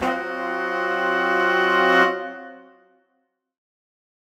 Index of /musicradar/undercover-samples/Horn Swells/C
UC_HornSwell_Cminb5.wav